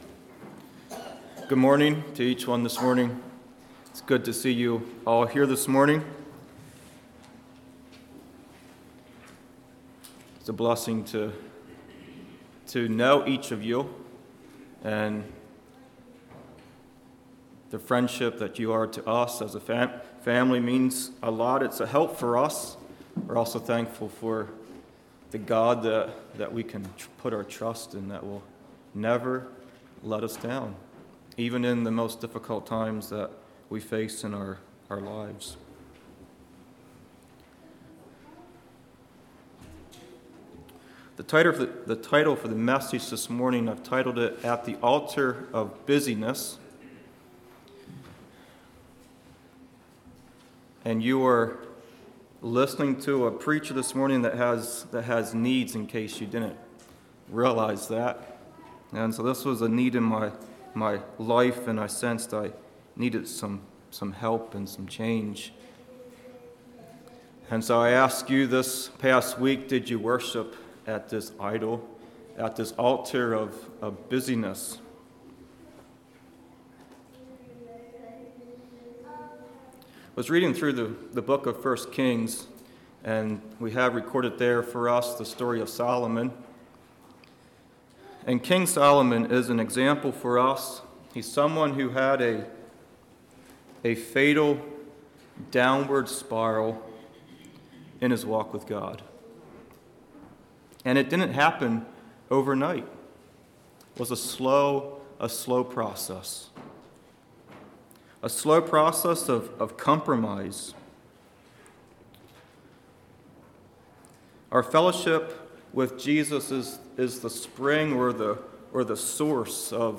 Congregation: Kirkwood